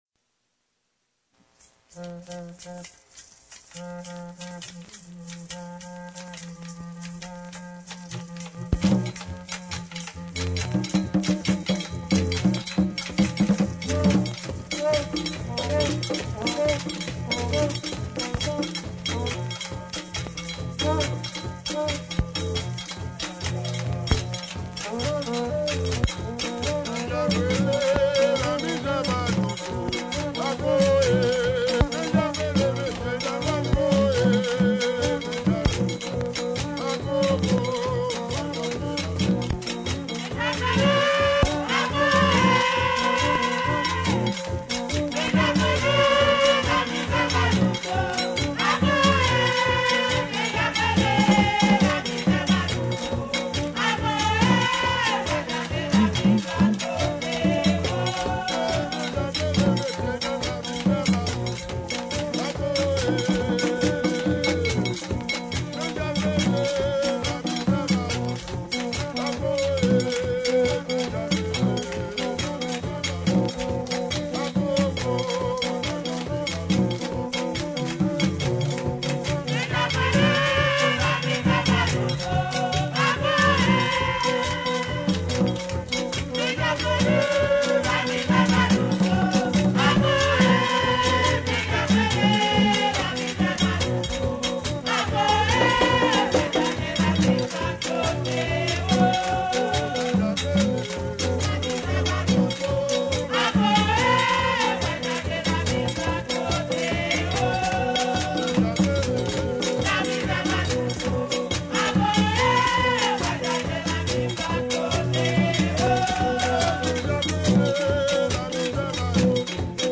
2022 RARA D'HAITI (VACCINE, FLOKLORE HAITIEN) audio closed https